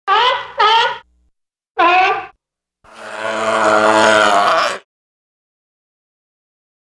دانلود آهنگ حیوان آبی 5 از افکت صوتی انسان و موجودات زنده
دانلود صدای حیوان آبی 5 از ساعد نیوز با لینک مستقیم و کیفیت بالا
جلوه های صوتی